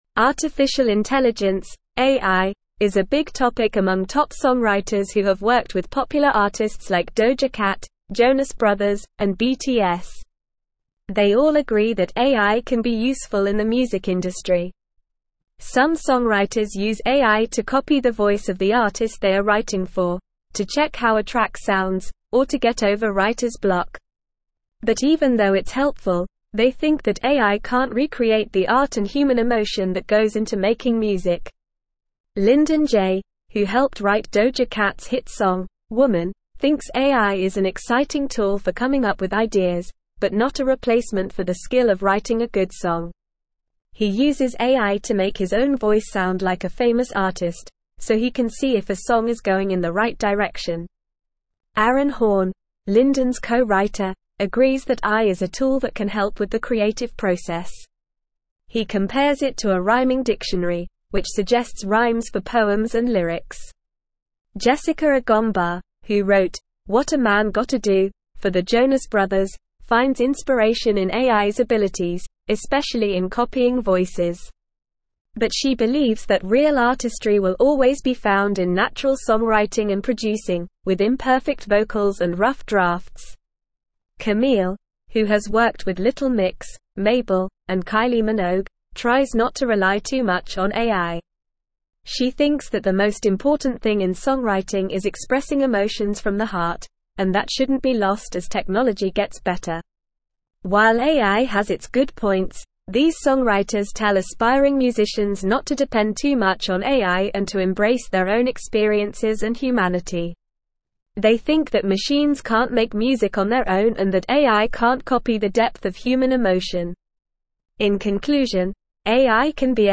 Normal
English-Newsroom-Upper-Intermediate-NORMAL-Reading-Top-Songwriters-Embrace-AI-as-a-Creative-Tool.mp3